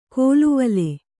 ♪ kōluvale